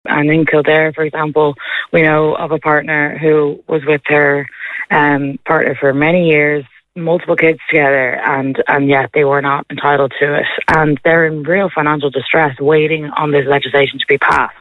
Speaking on Kildare Today